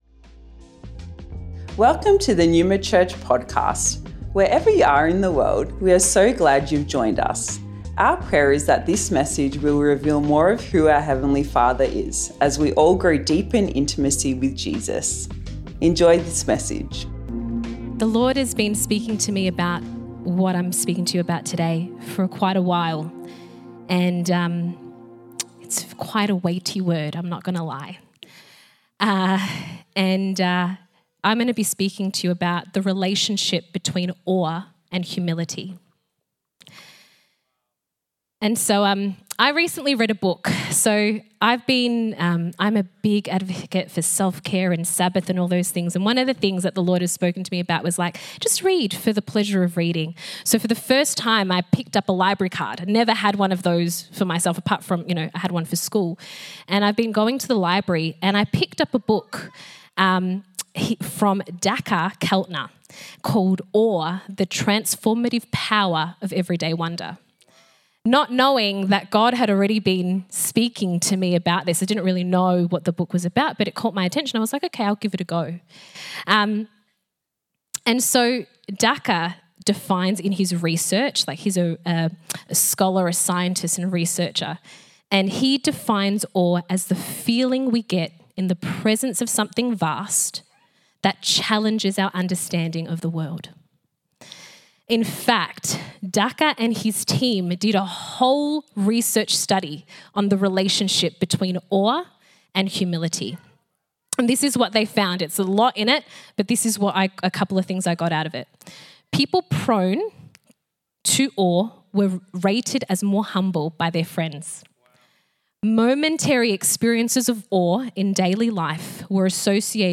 Originally recorded at Neuma Melbourne West July 21st 2024